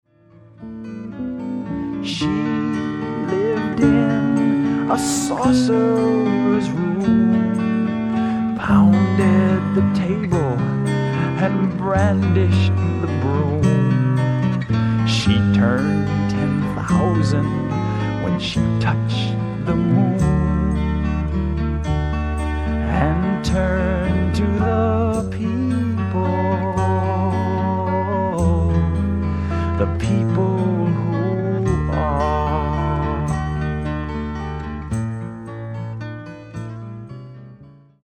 COUNTRY ROCK